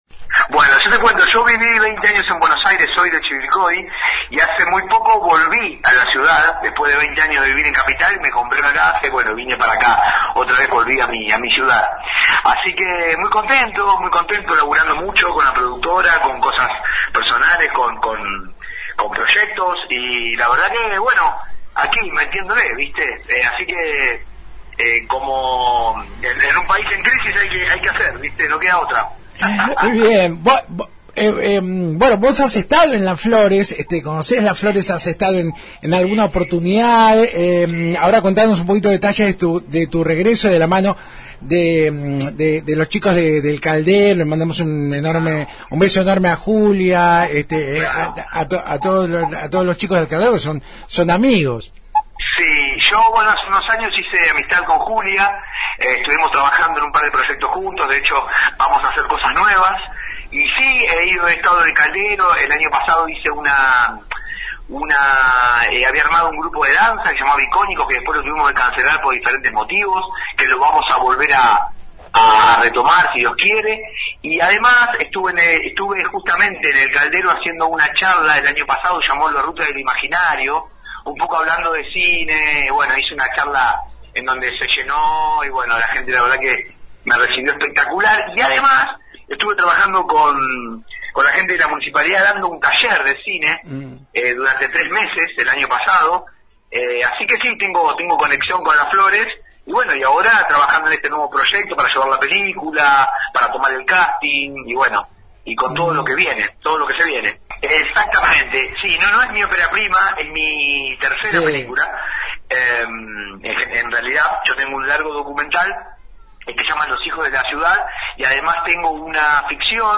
Para el film recuerdo que elegimos el ex frigorífico porque me pareció un lugar ideal para filmar algunas escenas que el guión requería», expresó este martes en comunicación telefónica con FM Alpha. Además de nuestra ciudad, «12 Apóstoles» también se filmó en Chivilcoy y Capital Federal.